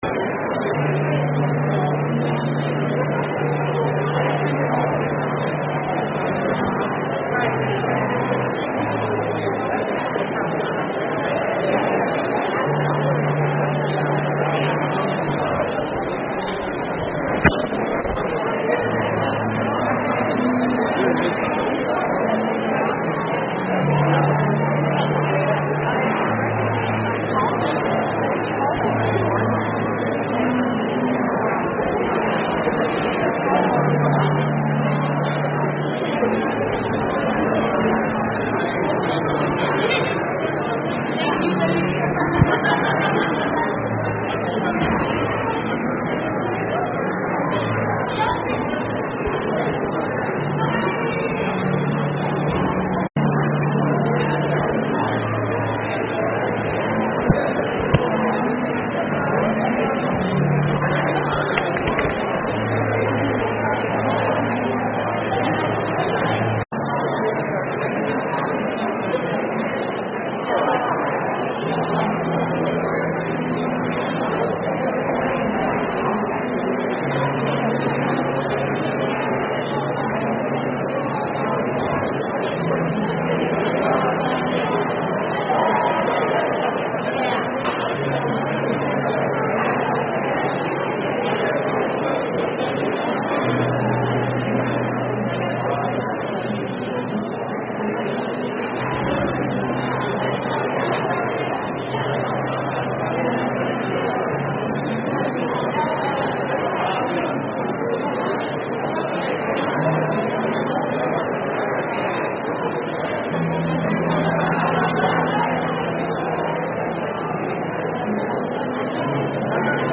Video Only: Requiem of Hope - Full concert performance